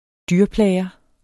Udtale [ ˈdyɐ̯ˌplæːjʌ ]